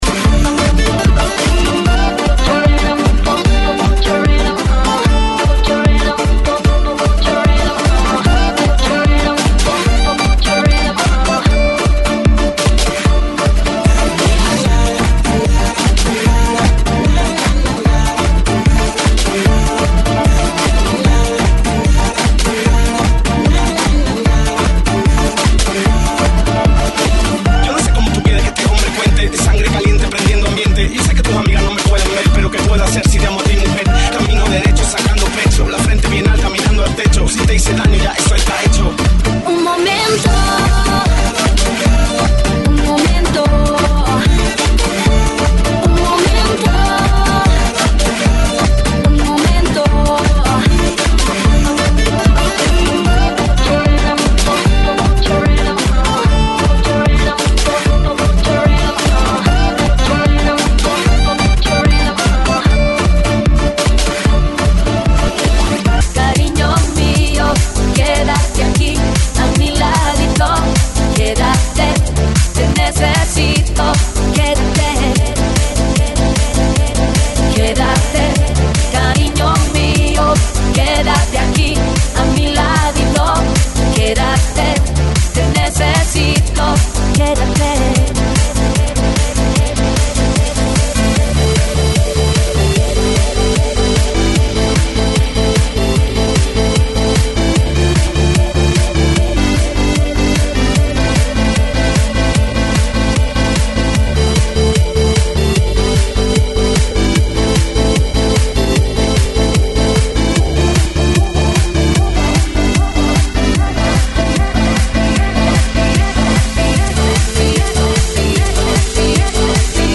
GENERO: LATIN